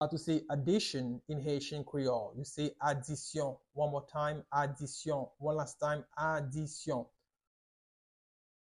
Pronunciation:
3.How-to-say-Addition-in-Haitian-–-Adisyon-Creole-with-pronunciation.mp3